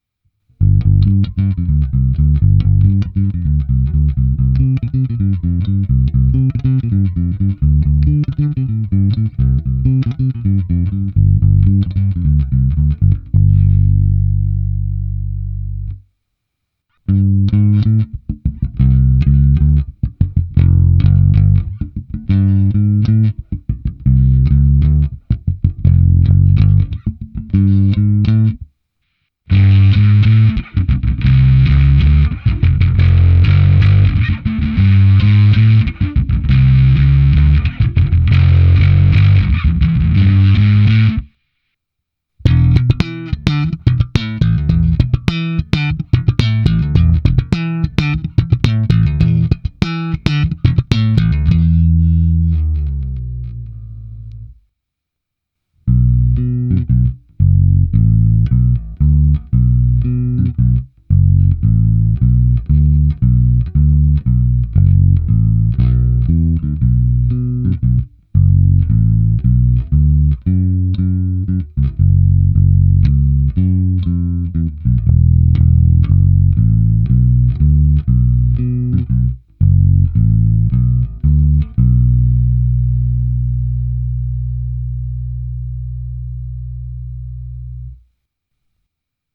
Basa si krásně rozumí se zkreslením a co víc, až překvapivě výtečně zní slap a báječně se na ni slapuje (ještě kdybych to víc uměl). Následující ukázka je prohnaná kompresorem TC Electronic SpectraComp (recenze) a taky preampem Darkglass Alpha Omega Ultra (recenze) se zapnutou simulací boxu 8x10".
Nahrávka přes Darkglass, se zkreslením i slapem